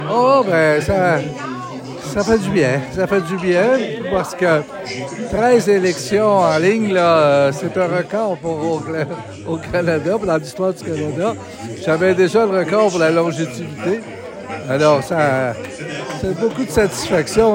Quelques instants après sa victoire, il a livré ses premiers commentaires.